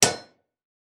Blacksmith hitting hammer 9.wav